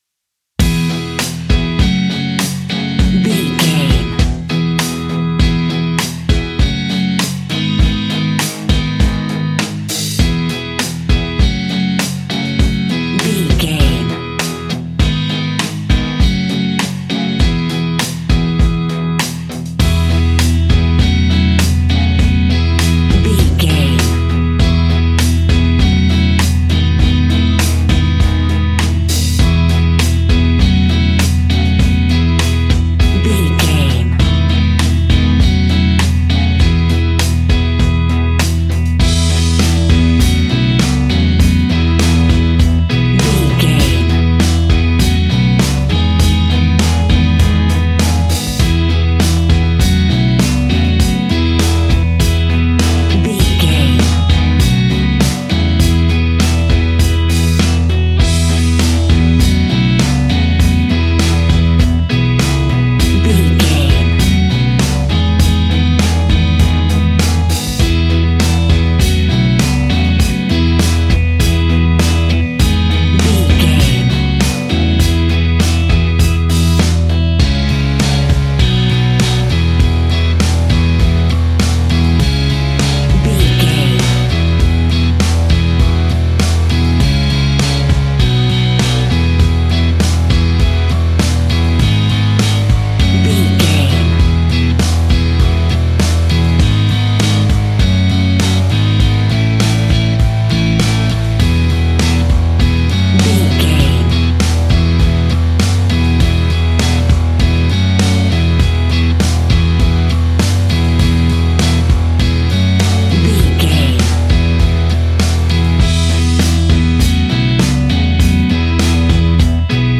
Ionian/Major
energetic
uplifting
instrumentals
indie pop rock music
upbeat
groovy
guitars
bass
drums
piano
organ